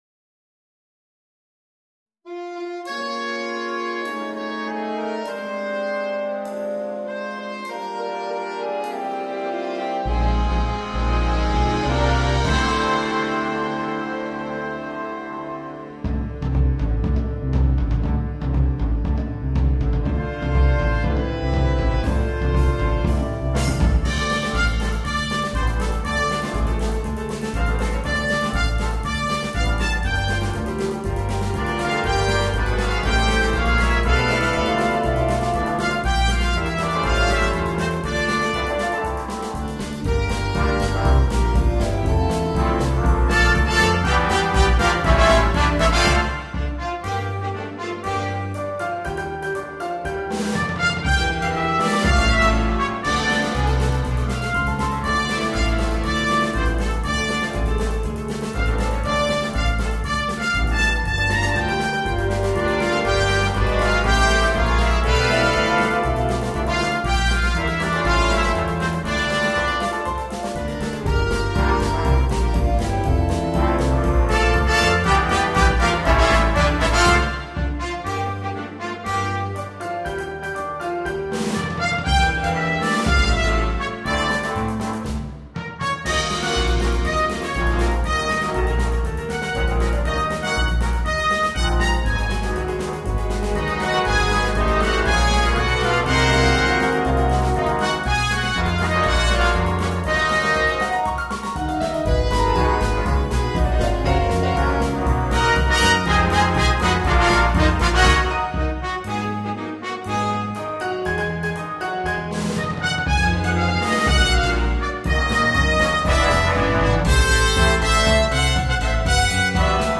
для биг-бэнда